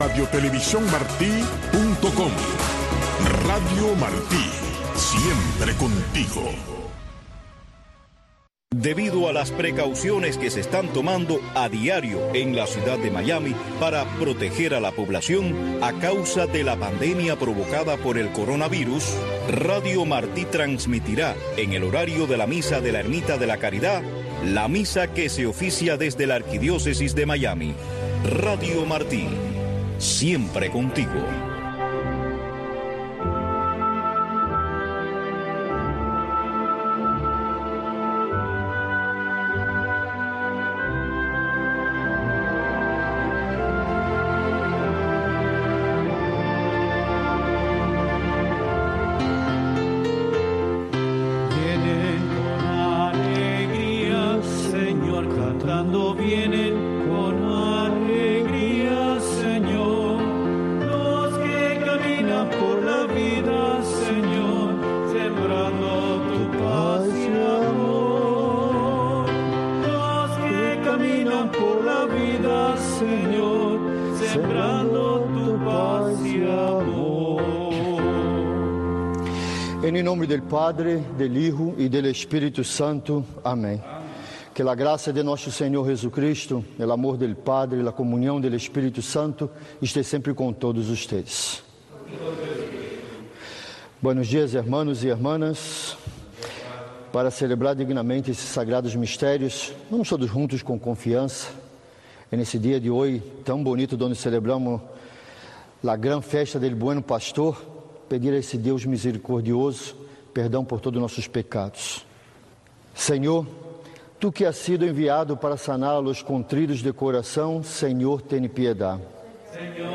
La Santa Misa
El Santuario Nacional de Nuestra Señor de la Caridad, más conocido como la Ermita de la Caridad, es un templo católico de la Arquidiócesis de Miami dedicado a Nuestra Señora de la Caridad, Patrona de Cuba.